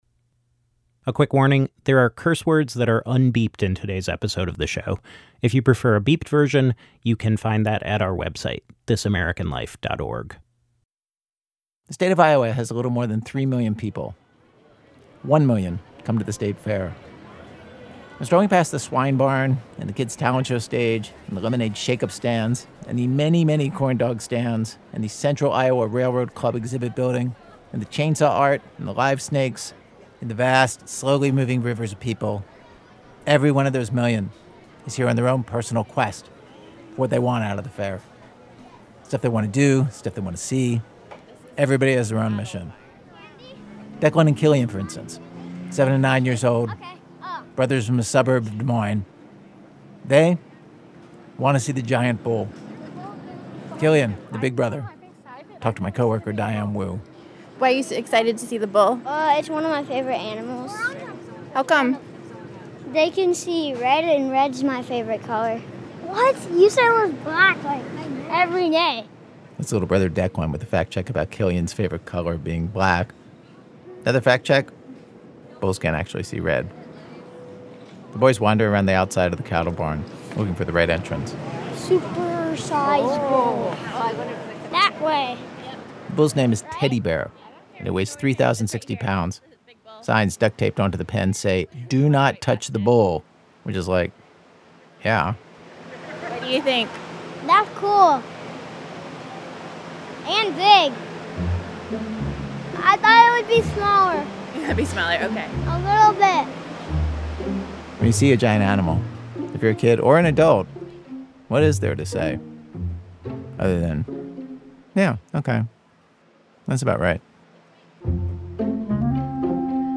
We spend a few days at the Iowa State Fair.
Note: The internet version of this episode contains un-beeped curse words.